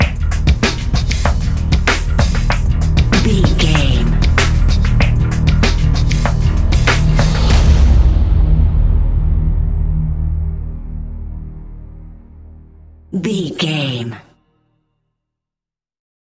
Ionian/Major
D♯
electronic
techno
trance
synths
synthwave
glitch
instrumentals